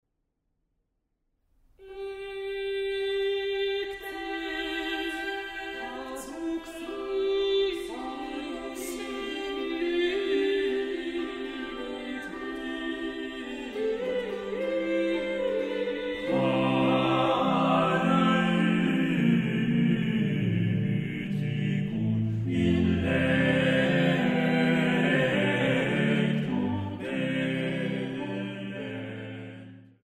Leitung und Orgel
SEX VOCUM